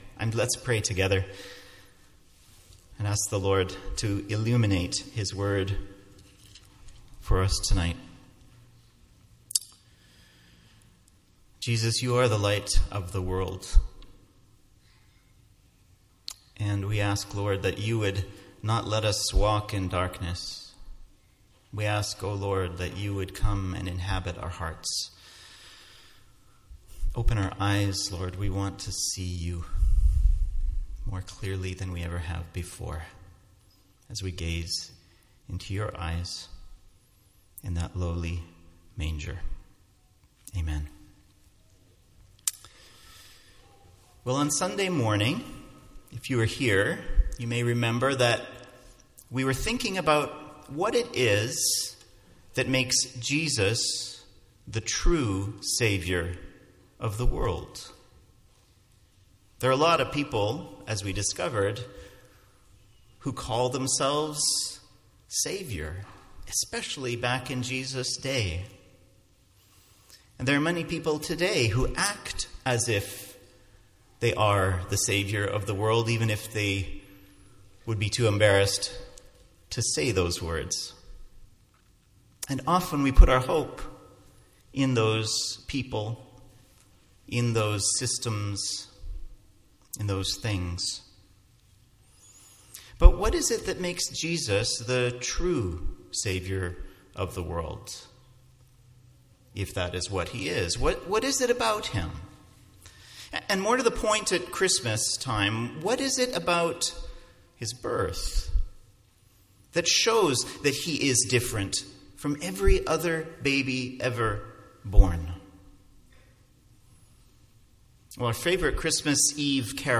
MP3 File Size: 15.2 MB Listen to Sermon: Download/Play Sermon MP3